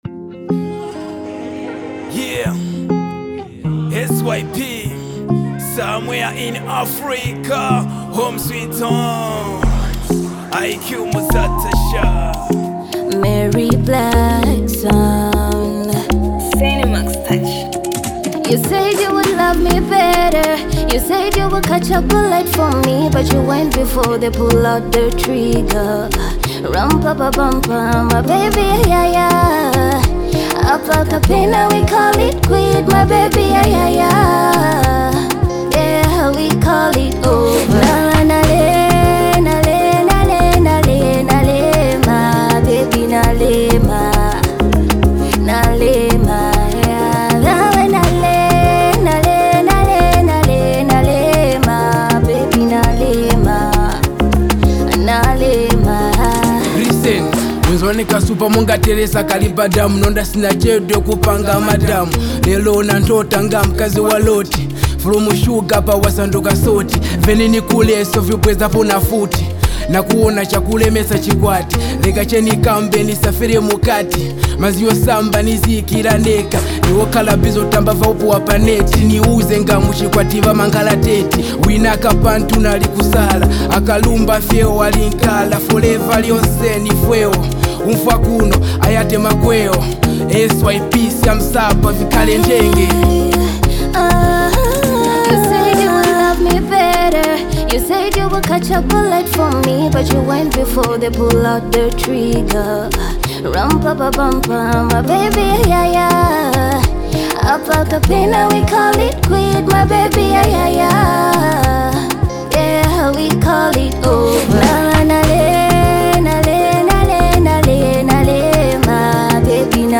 heartfelt track